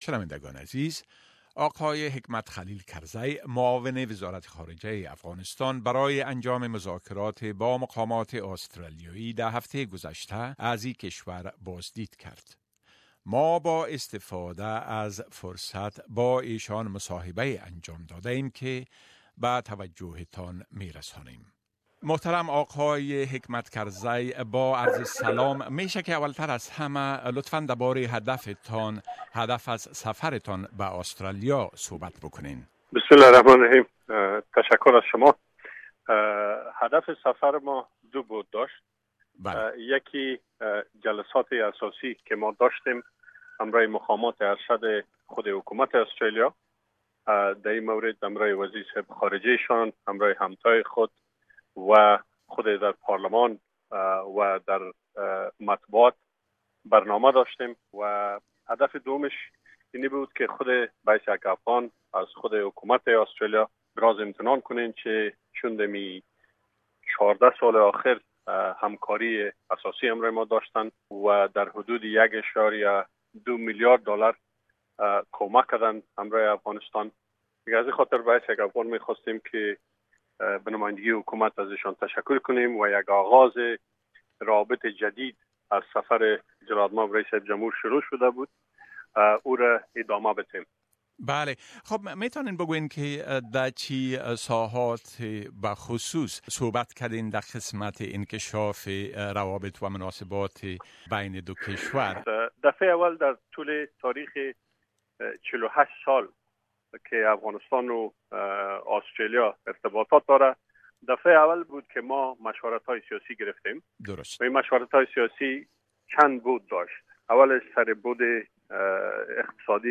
Interview with the visiting Afghan deputy foreign minister Mr Hekmat Karzai